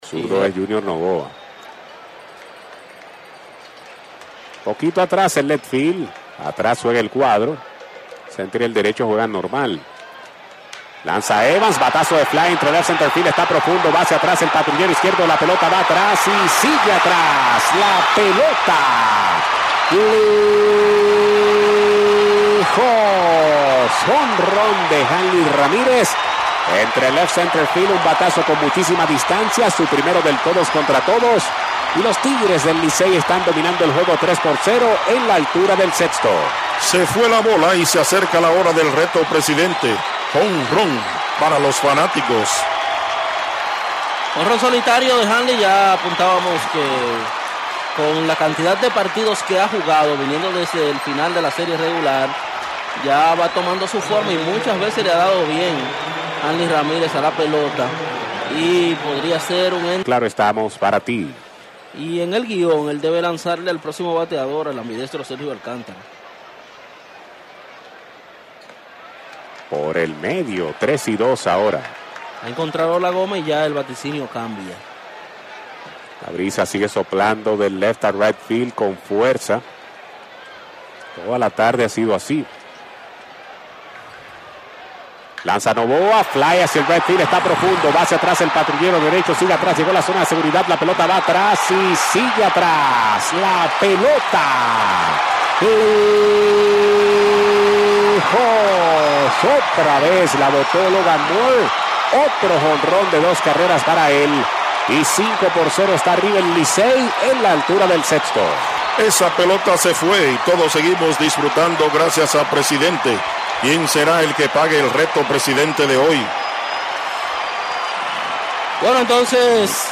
Transmision-RADIO-HRS-del-Licey.mp3